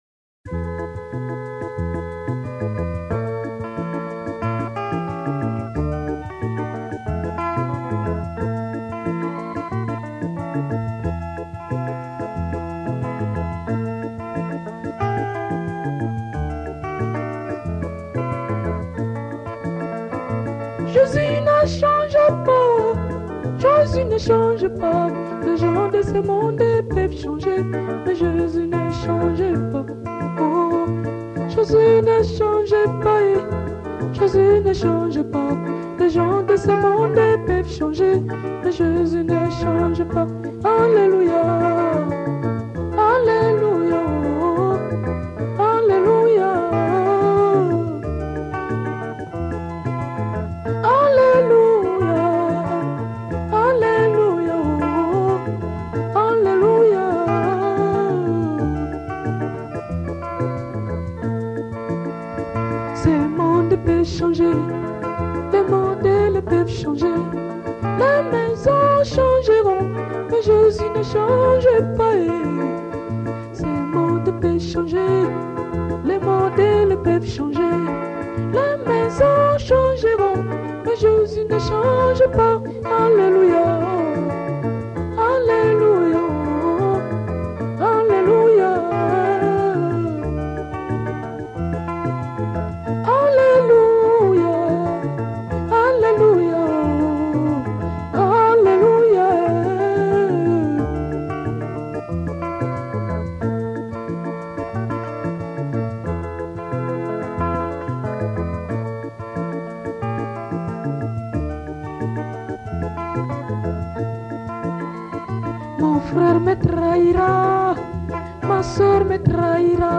Gospel 1992